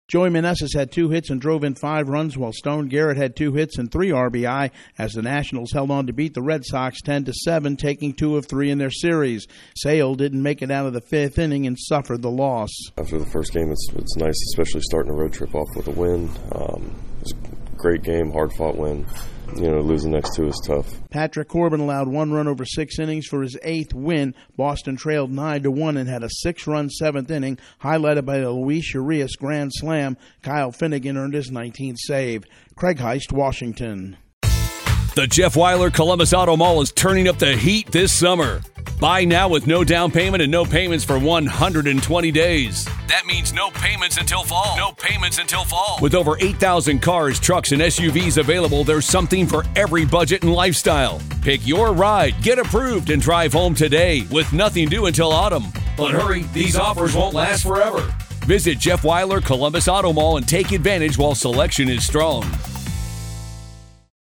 The Nationals push Chris Sale and the Red Sox further behind the wild-card spots. Correspondent